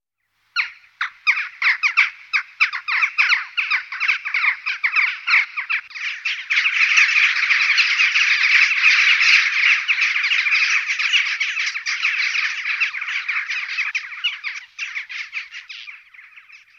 На этой странице собраны звуки галки – от характерных криков до пересвистов с сородичами.
Стая галок